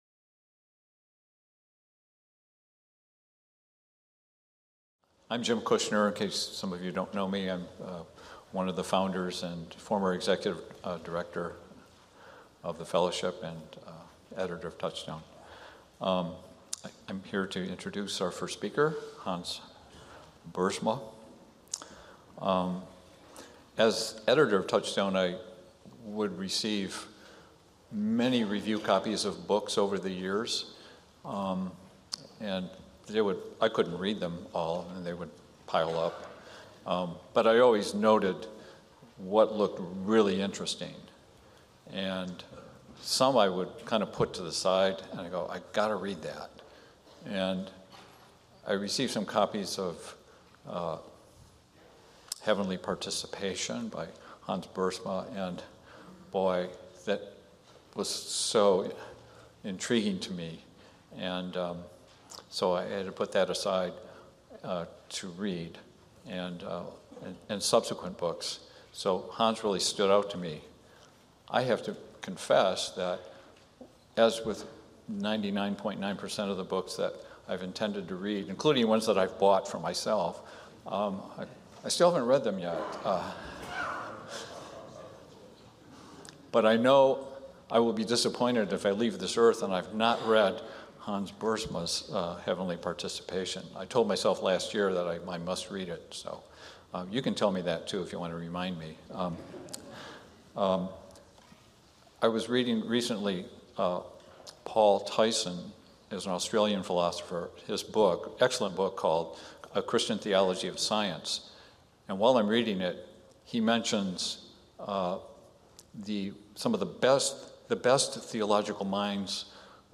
Duration: 45:54 with discussion afterwards — Talk delivered on Thursday, October 14, 2022